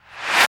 69 RV CLAP-R.wav